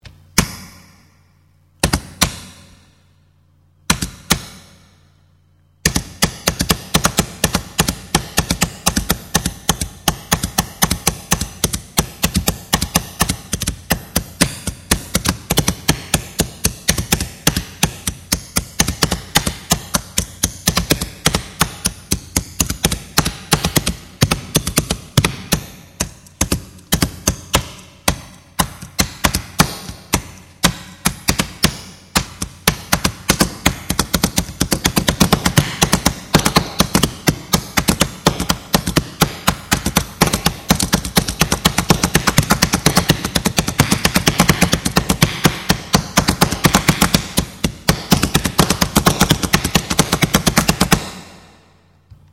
Pen drumming is basically finger drumming, but using two pens as drum sticks.
The drumming can be a little more precise and has a more defined sound.
Basic Pen Drumming
pendrumming.mp3